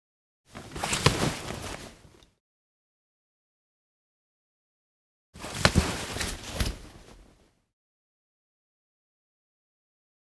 household
Hockey Equipment Bag Pick Up 2